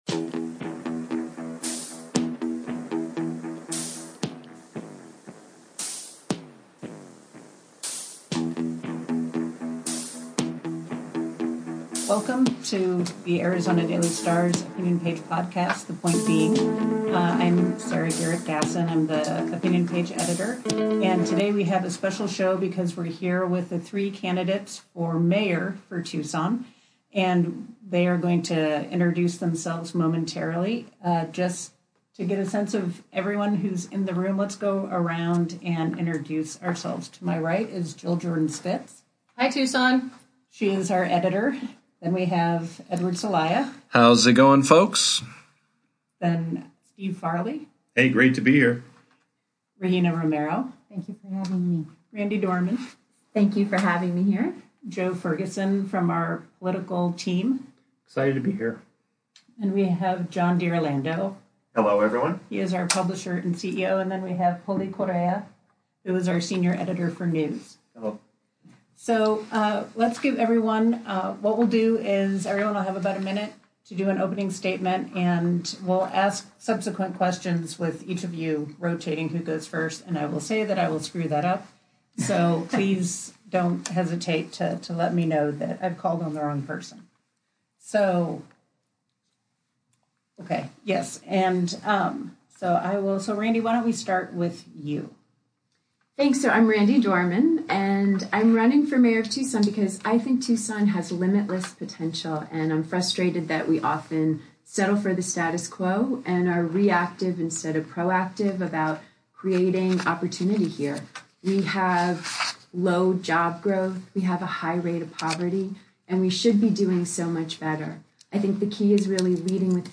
Our Editorial Board sat down with the three Democrats running for mayor of Tucson on Tuesday, July 23.